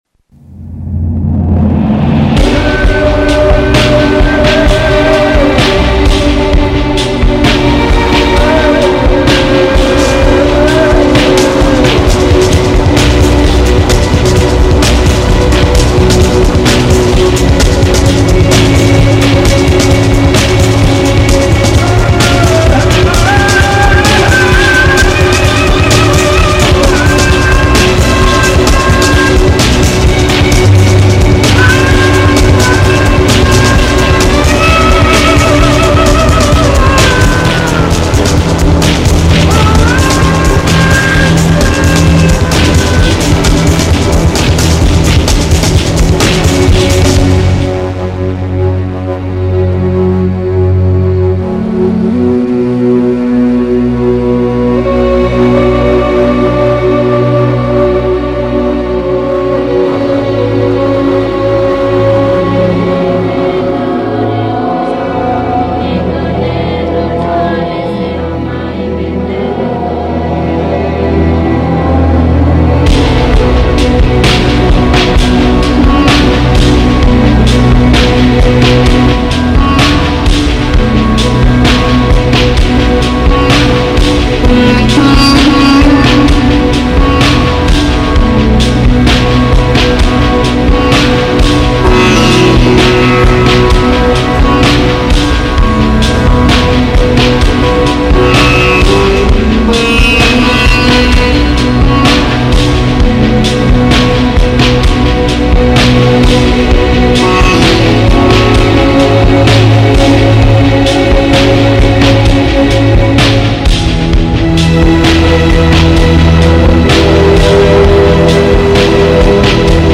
Aufgenommen in: glasbeni arboretum, studio fake
violins, violas, bass
cello
bass
voices of the children of the world